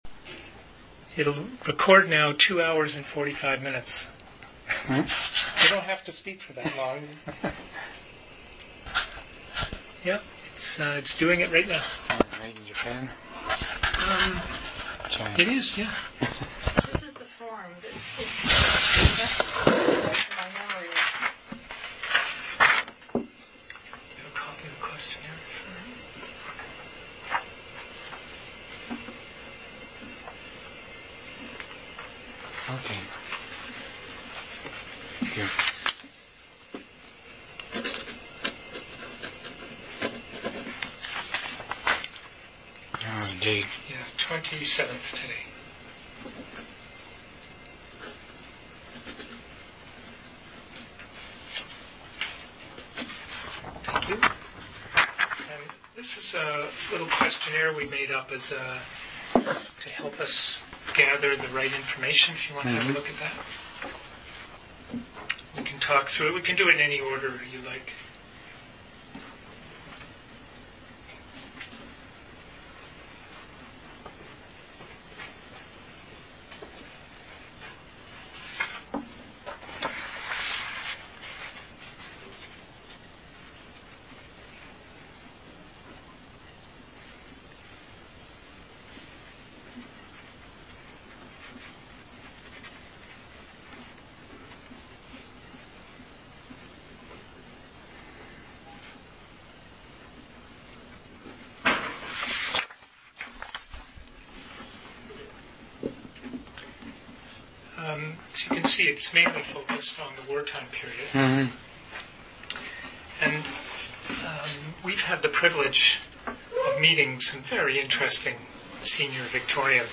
Canadian Military Oral Histories